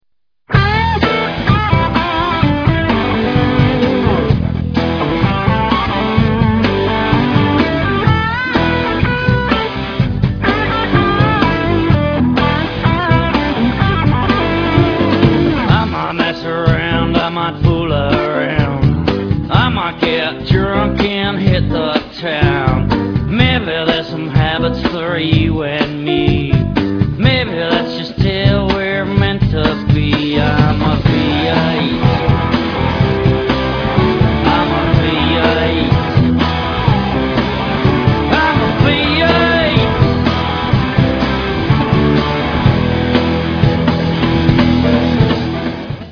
Real Audio Stereo Clip                                               Mono Wave Clip